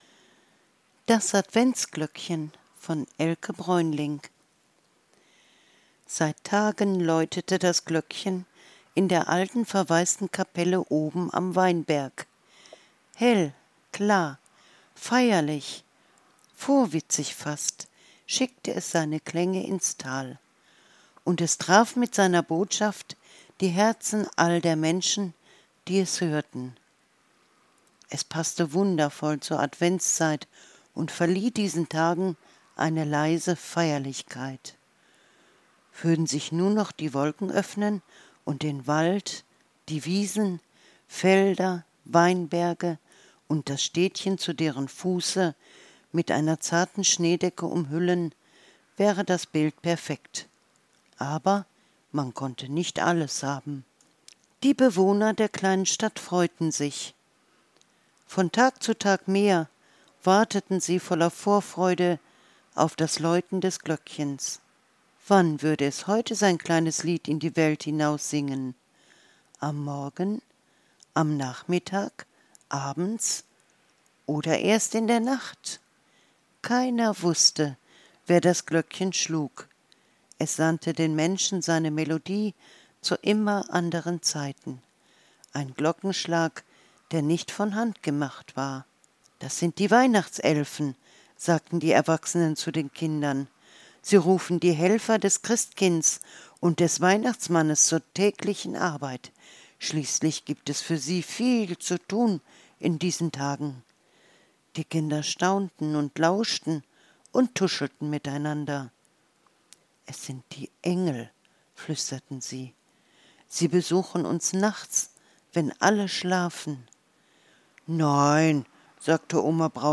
Adventsgeschichte für Klein und Groß